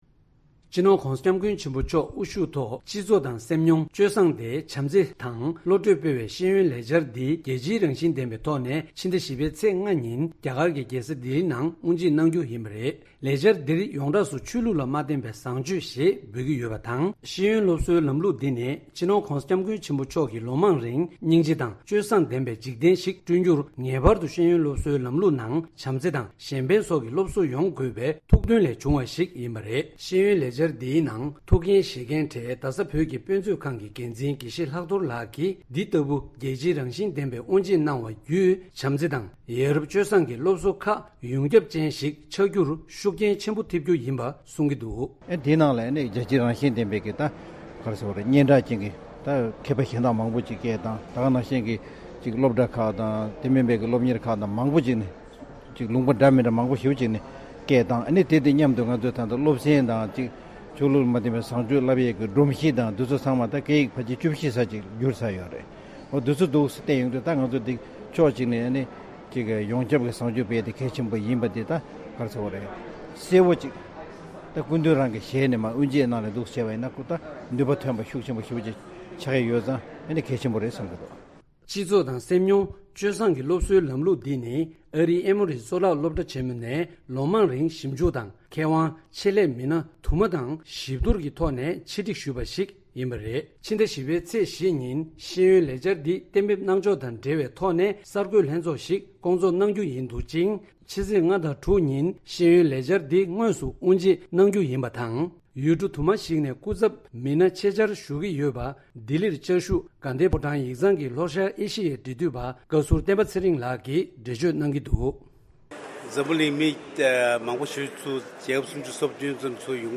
ས་གནས་ནས་བཏང་བའི་གནས་ཚུལ།